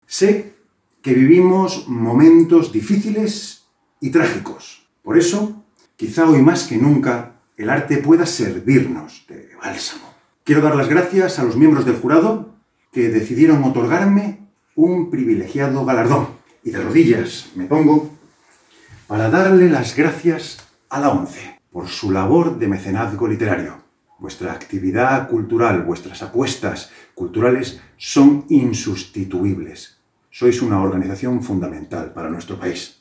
Todos los premiados  agradecen el galardón en una gala difundida en Youtube con intervención de todos los jurados y responsables institucionales